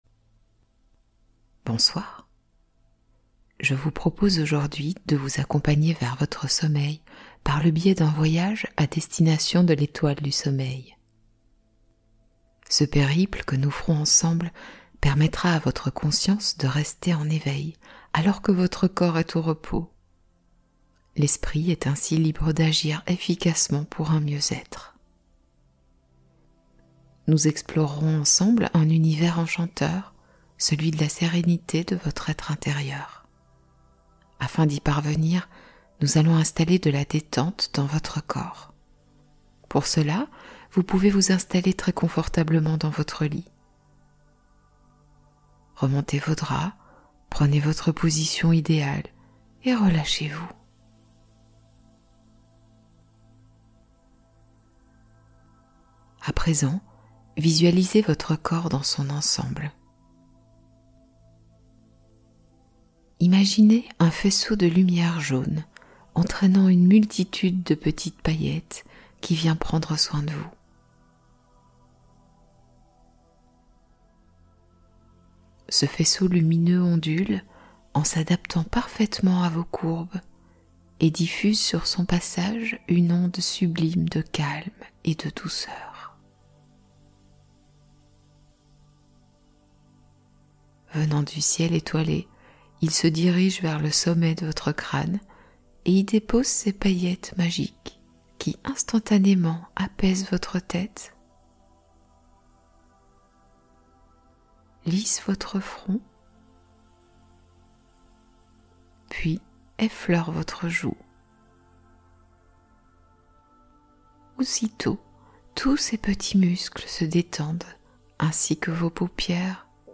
Sommeil cocon : hypnose de sécurité et bien-être total